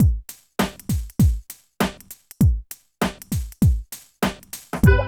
70 DRUM LP-L.wav